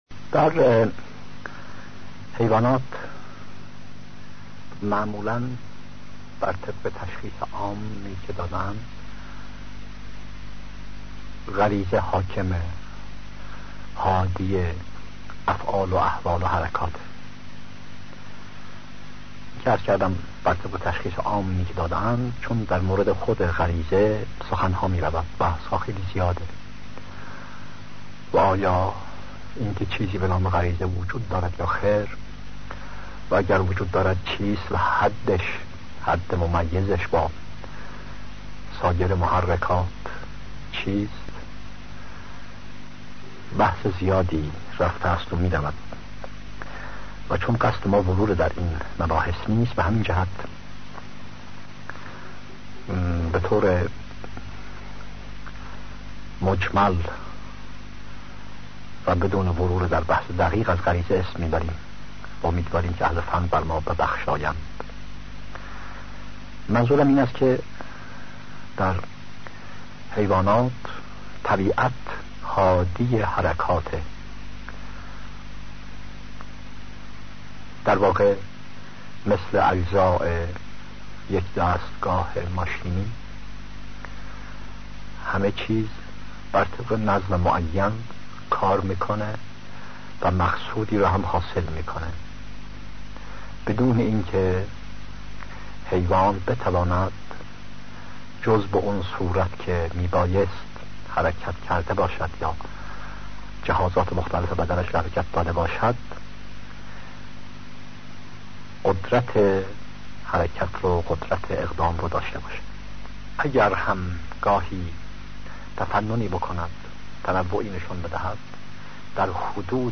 سخنرانی هایی پیرامون عقاید بهائی